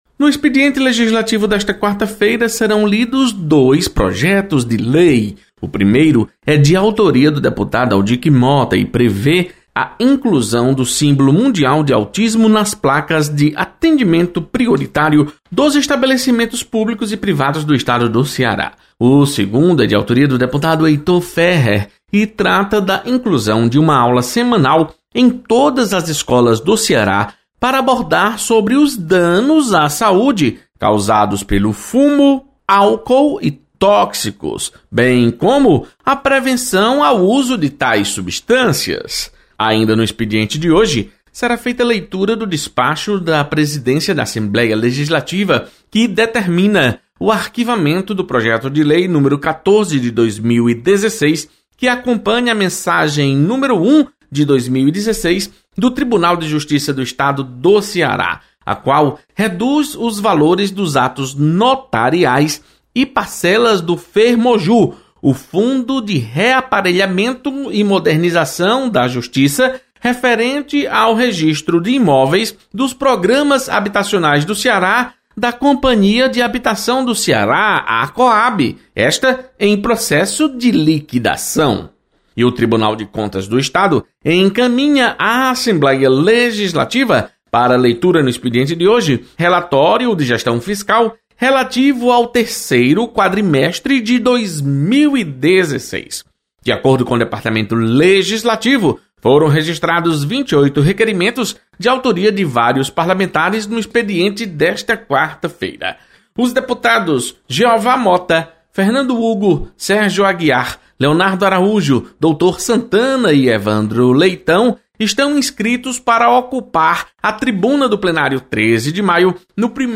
Acompanhe as informações do expediente legislativo desta quarta-feira com o repórter